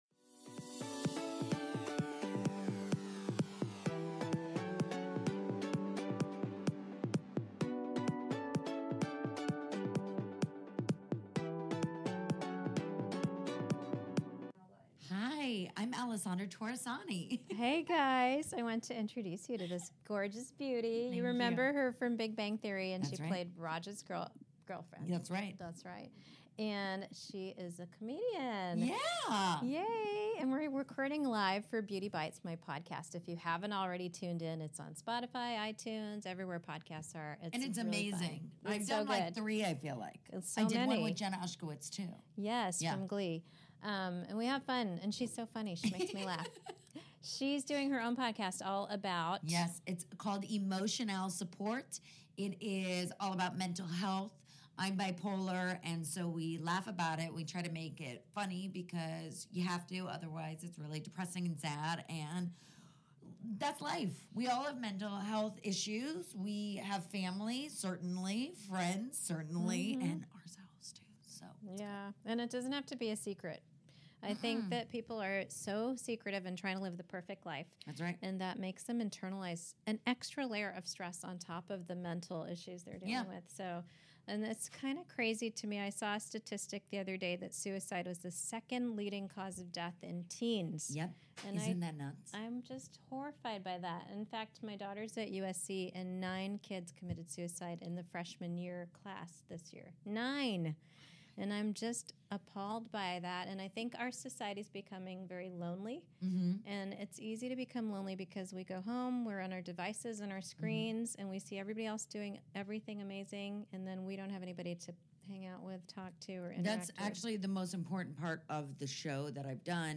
I’ve known Alessandra for quite some time and it is always a pleasure having her at my Pasadena office.
Things get a little crazy in the middle of the podcast once I start injecting but we always have a great time!